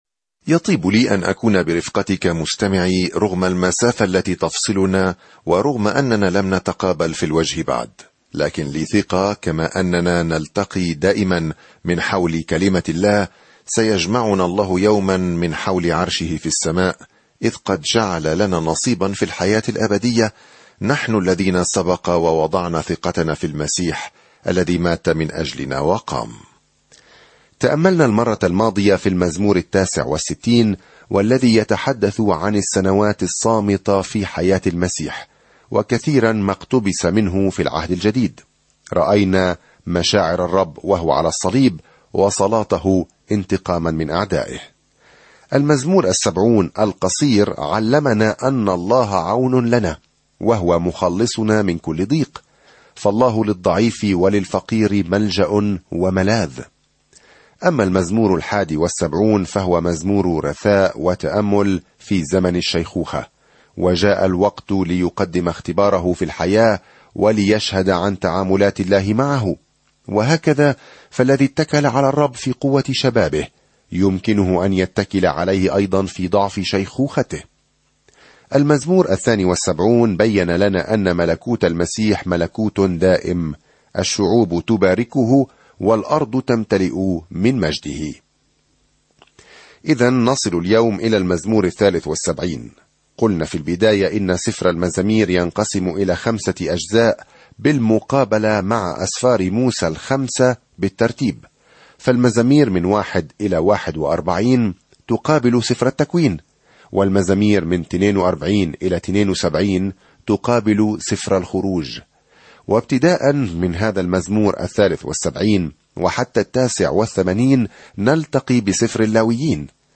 الكلمة اَلْمَزَامِيرُ 73 اَلْمَزَامِيرُ 74 اَلْمَزَامِيرُ 75 يوم 33 ابدأ هذه الخطة يوم 35 عن هذه الخطة تعطينا المزامير أفكارًا ومشاعر مجموعة من التجارب مع الله؛ من المحتمل أن كل واحدة تم ضبطها في الأصل على الموسيقى. سافر يوميًا عبر المزامير وأنت تستمع إلى الدراسة الصوتية وتقرأ آيات مختارة من كلمة الله.